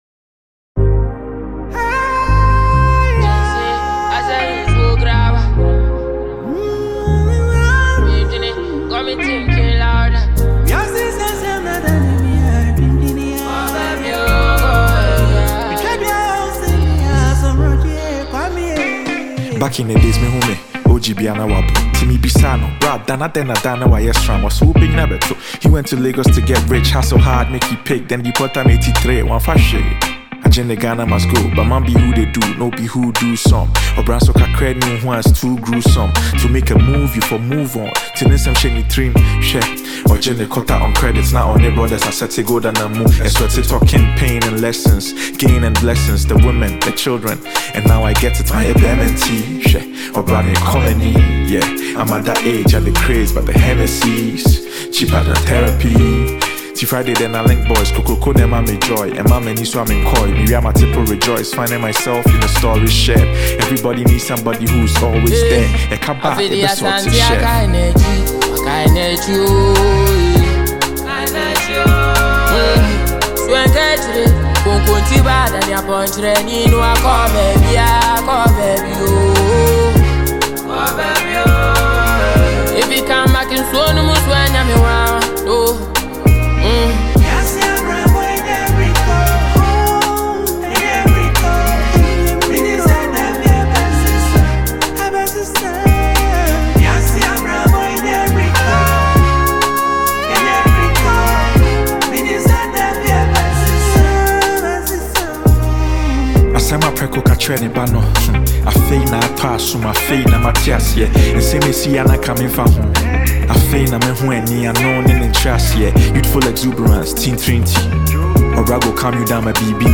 Check the latest tune from Ghanaian rapper